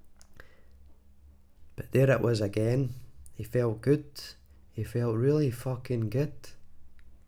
glaswegian
scottish